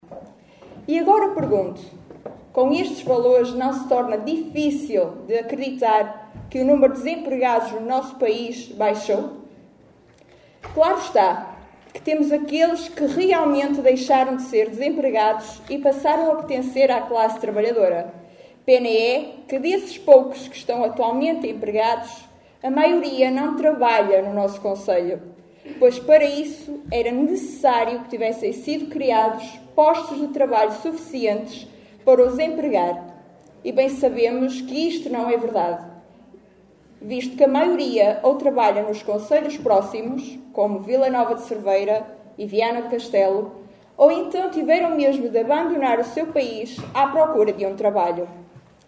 Extratos da última Assembleia Municipal de Caminha.